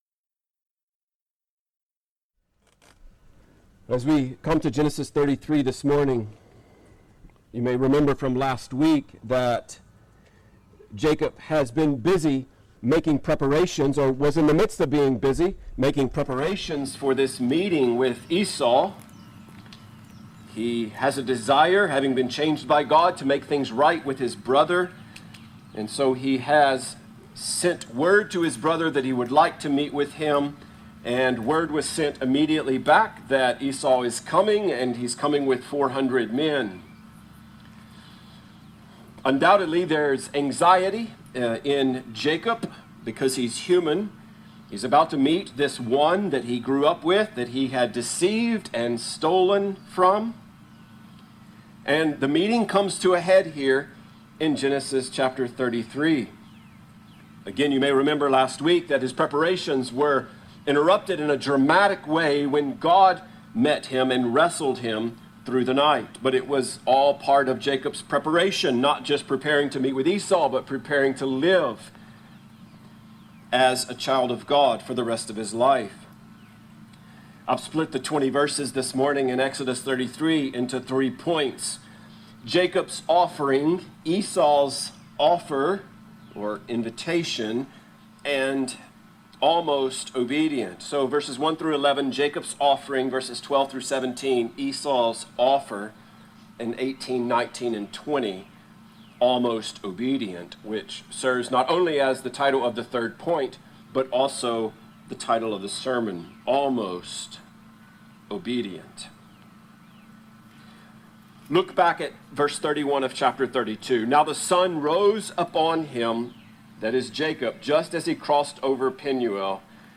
Almost Obedience | SermonAudio Broadcaster is Live View the Live Stream Share this sermon Disabled by adblocker Copy URL Copied!